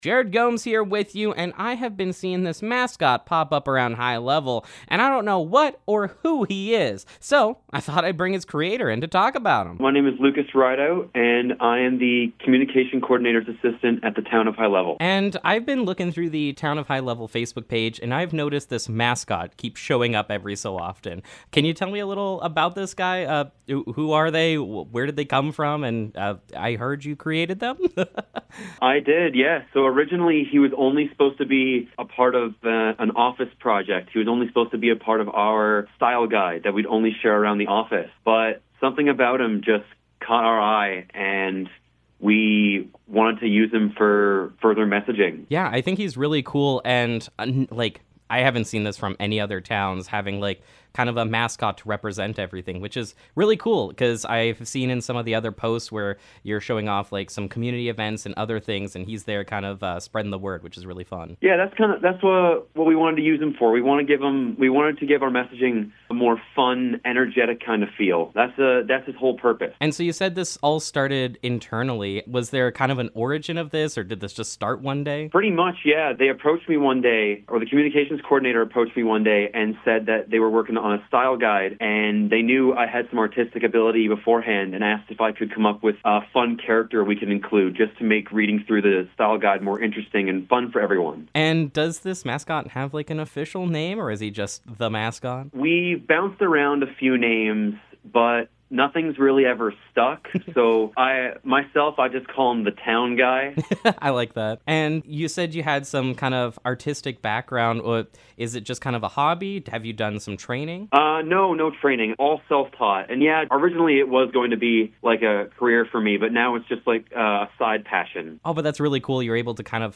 Town of High Level Mascot Interview
hl-mascot-interview-final.mp3